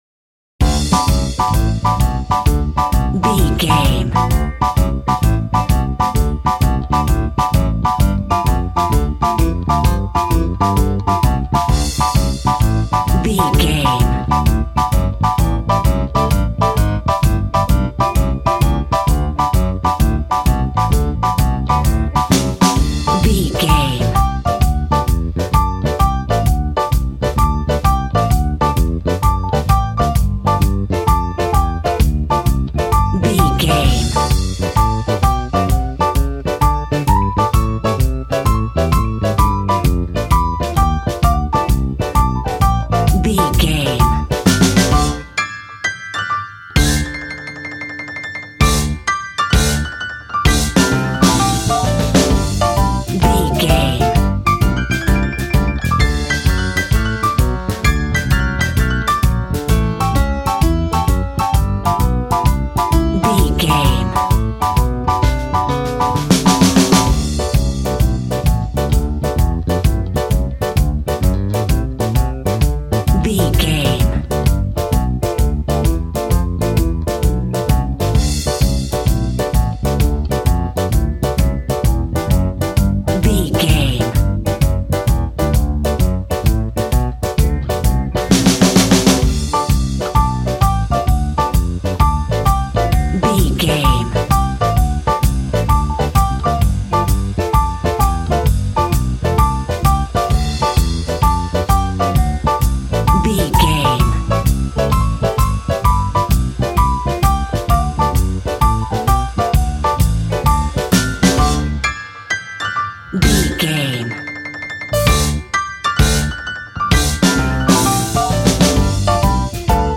Ionian/Major
D
sad
mournful
hard
bass guitar
electric guitar
electric organ
drums